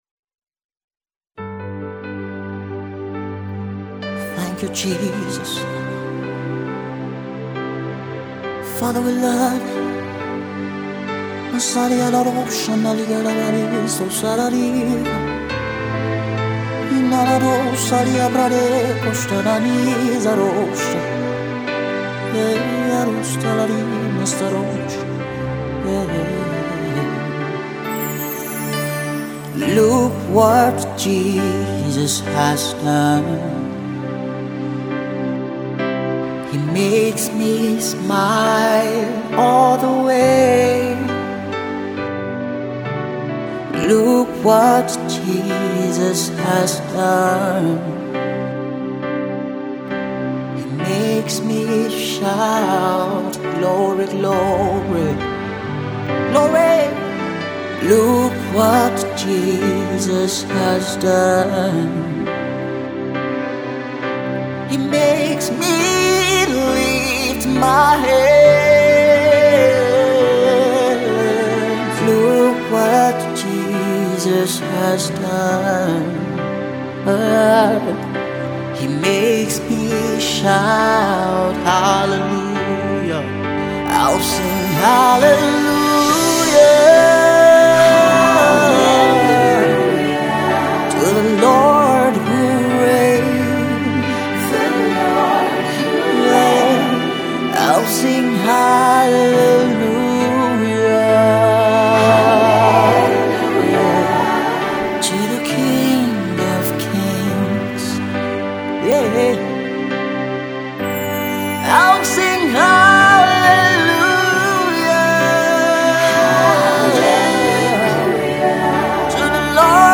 contemporary and soul music